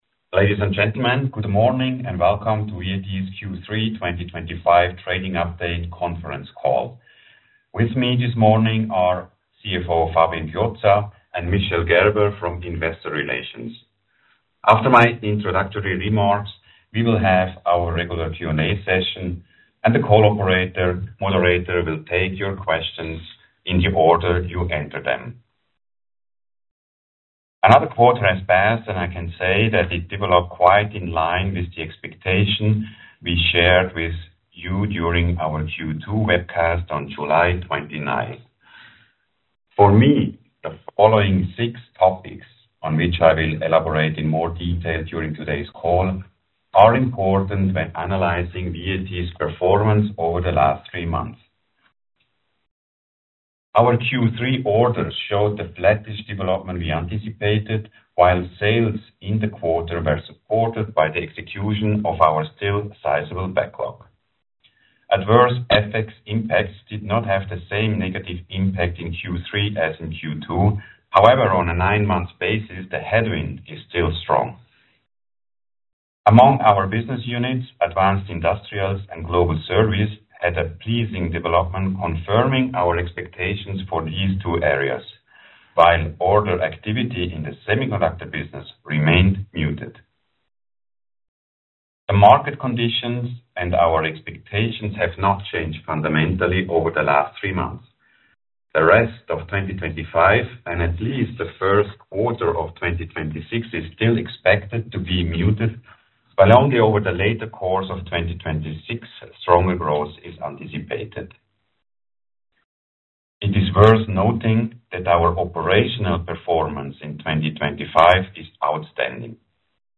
vat-conference-call-half-year-2025-results.mp3